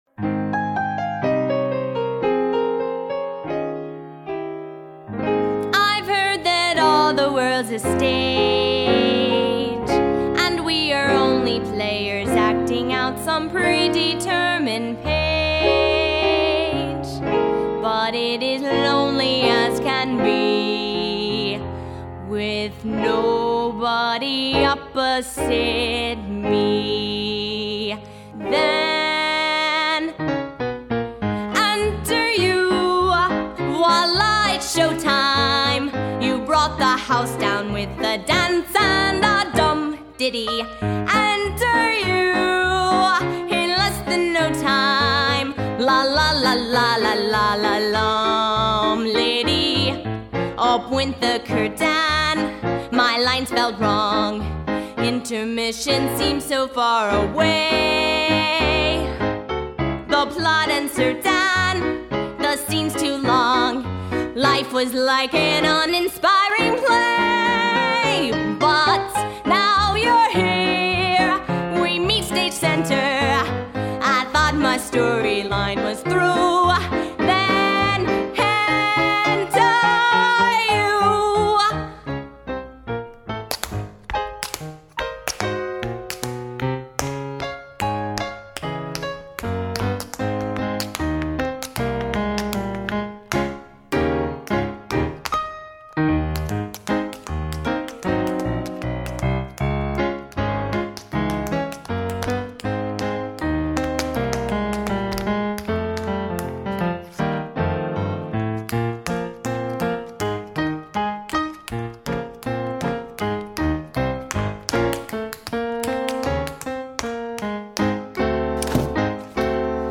1999   Genre: Soundtrack   Artist